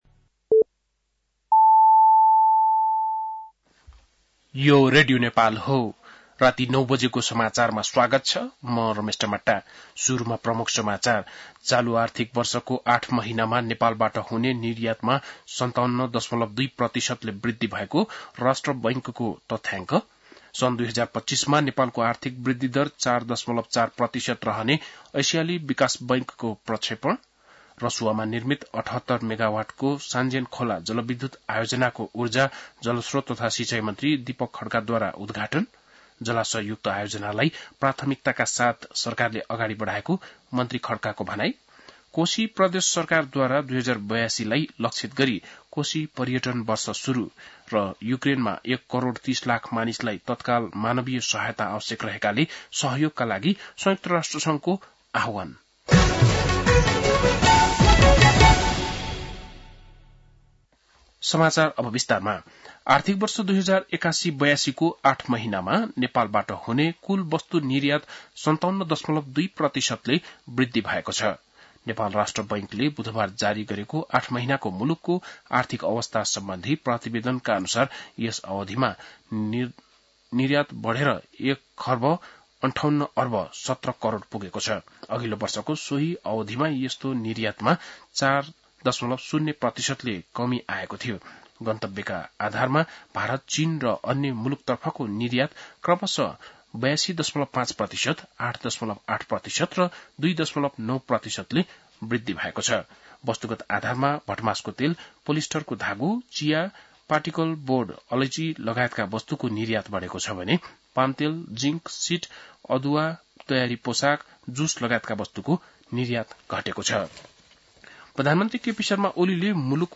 बेलुकी ९ बजेको नेपाली समाचार : २७ चैत , २०८१